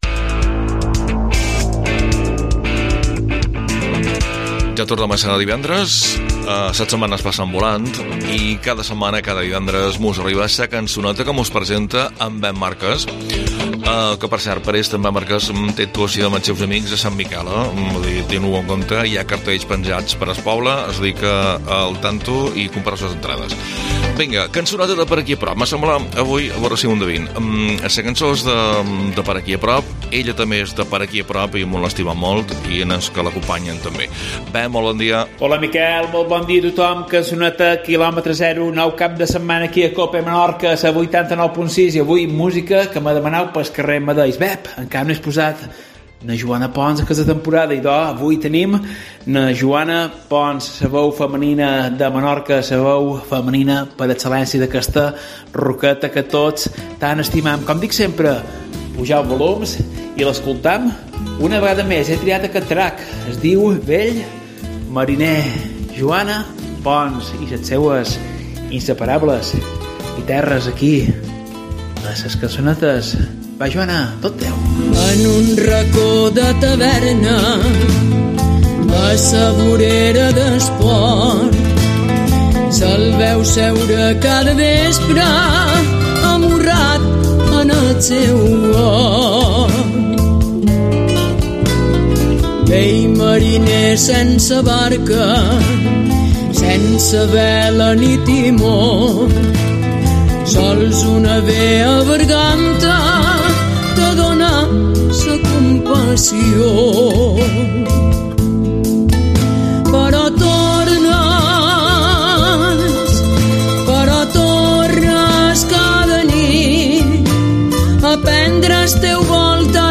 amb ses guiterres